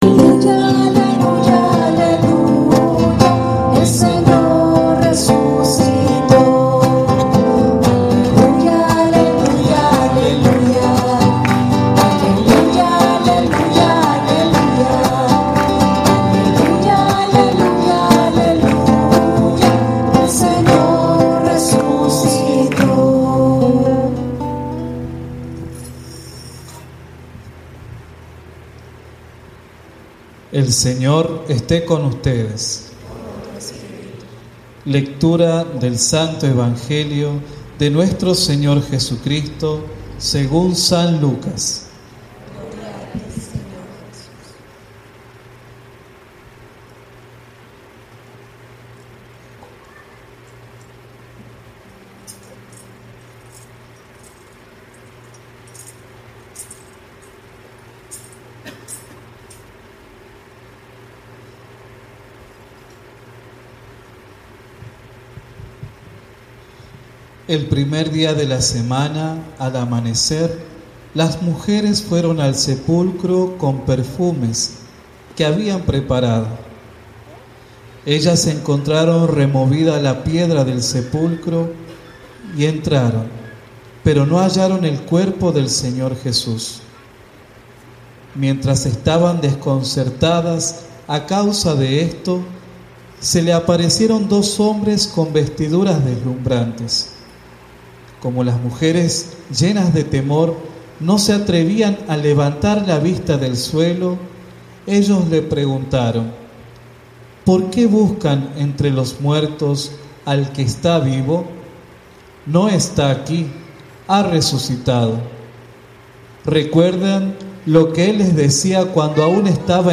Homilia Misa Sabado Santo